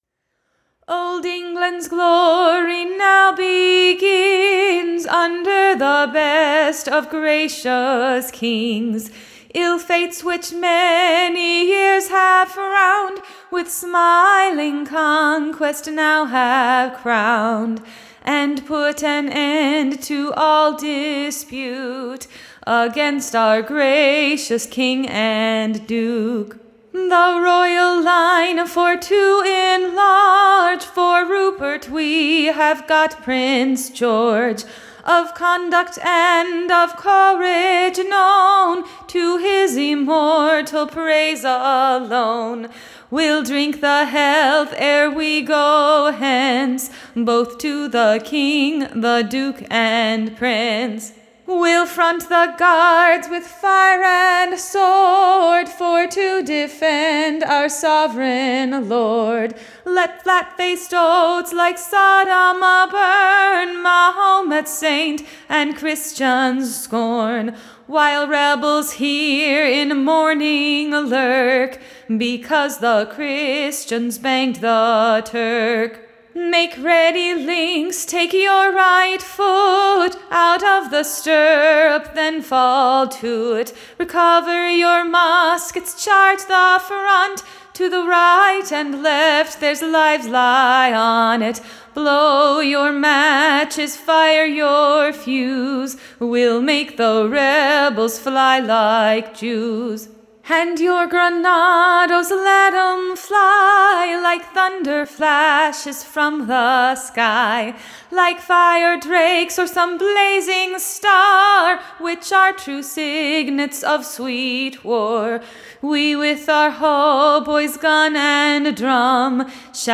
Recording Information Ballad Title THE / Grenadiers Loyal Health. / A SONG.